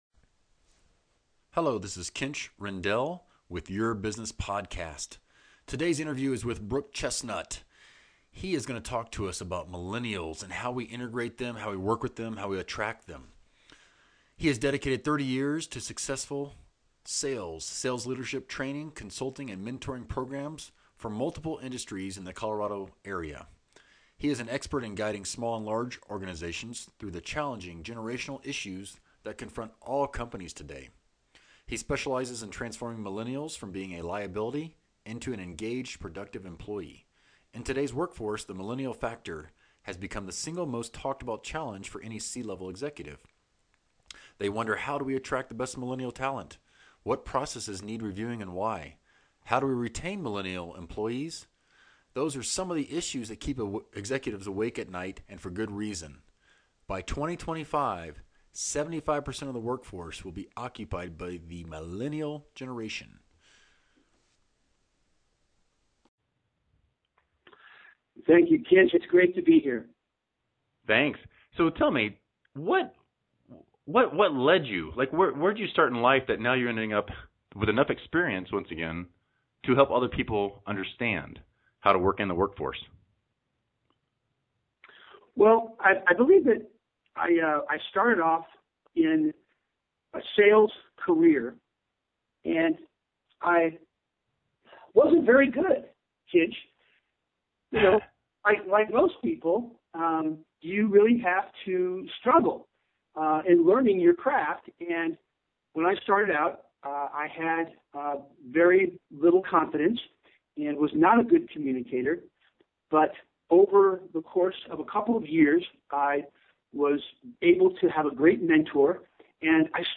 Interviews | Your Business Podcast